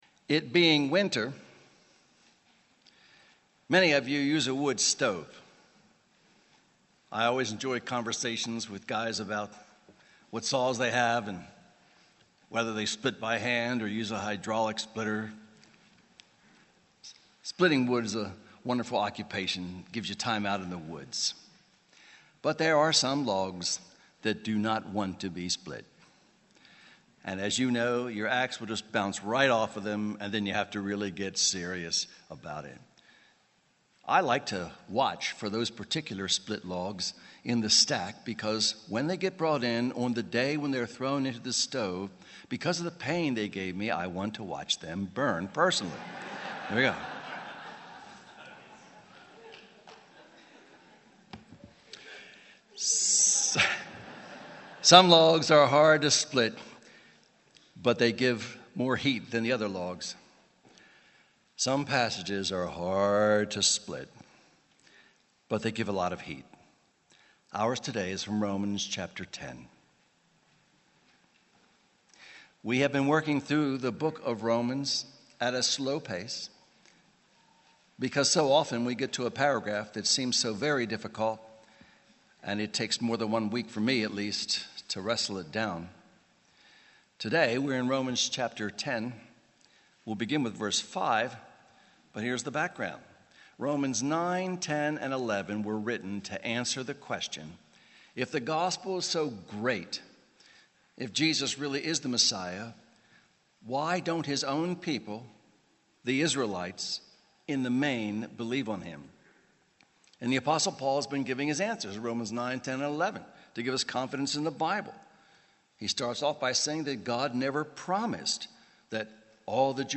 Sermon Series: Wrong Way to Heaven — Audio Sermons — Brick Lane Community Church